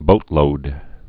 (bōtlōd)